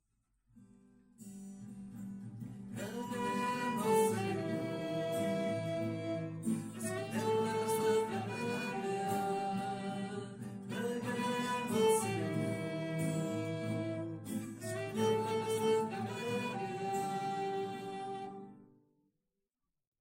Pregària de Taizé a Mataró... des de febrer de 2001
Capella dels Salesians - Diumenge 27 d'abril de 2025